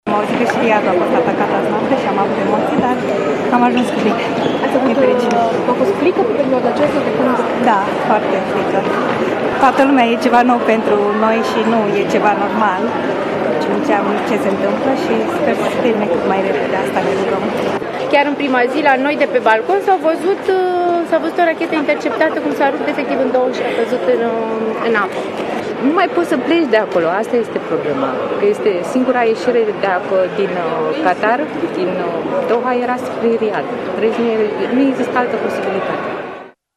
Toți au declarat jurnaliștilor aflați la aeroportul Otopeni să se bucură că au ajuns cu bine acasă.